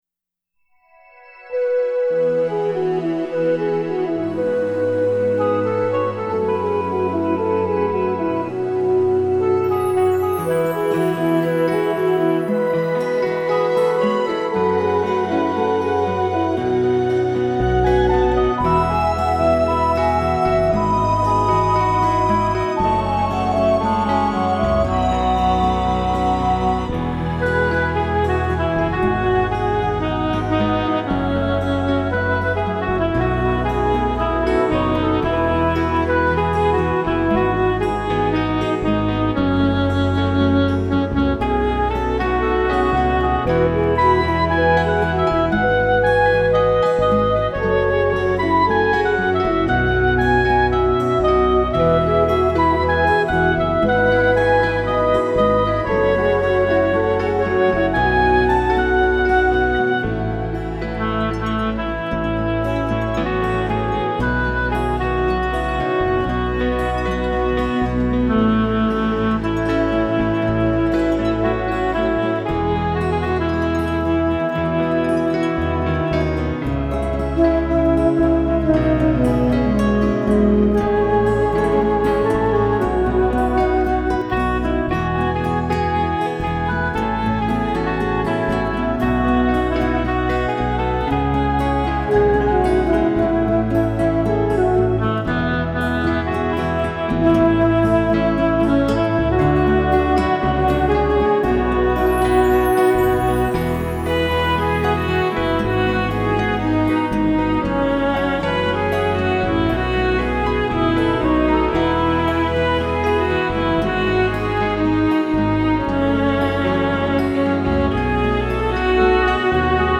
Let these instrumentals take you on a musical journey of healing with their touching melodies.
Later on, I gravitated more to acoustic guitar, piano and piano, and solo piano.